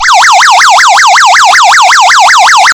5 Warning Sounds
Emergency WA-U(Yelp)